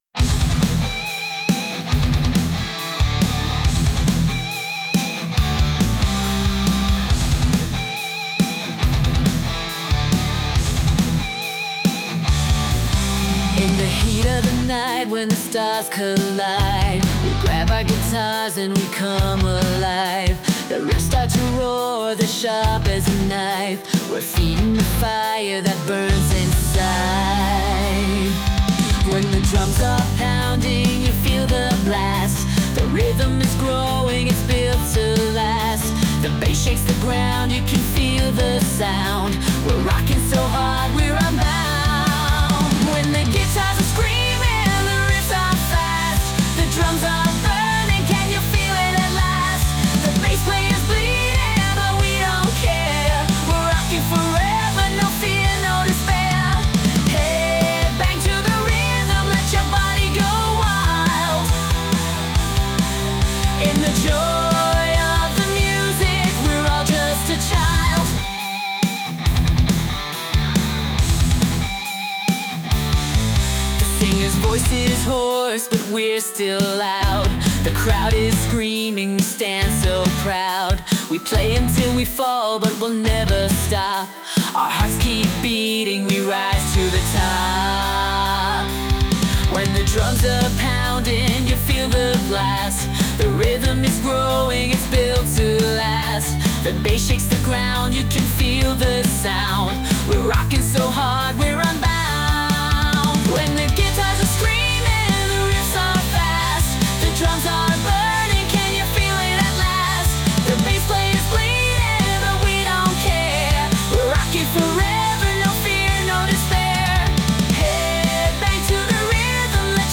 Ein Heavy Metal Experiment